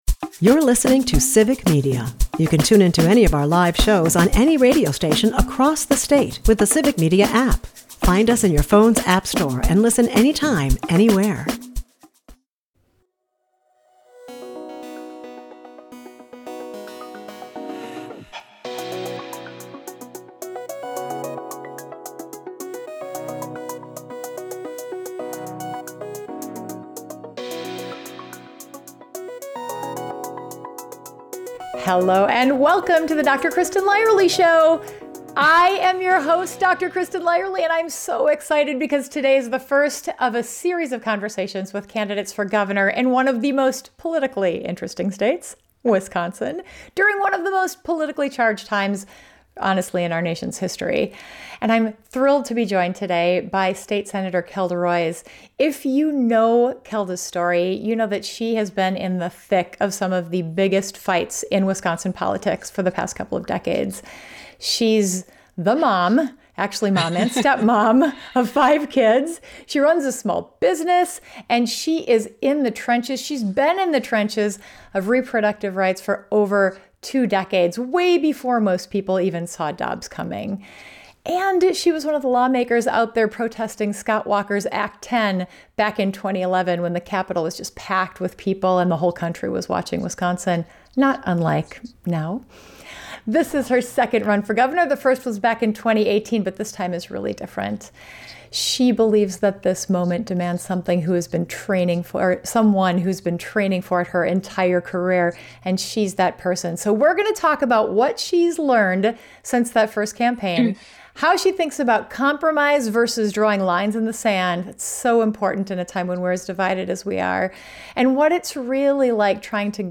In this wide-ranging conversation, we discuss how she thinks about leadership and compromise in a purple state, her vision for education and healthcare, what drives her advocacy work, and how she manages the chaos of running a small business and raising five kids while serving in the state Senate.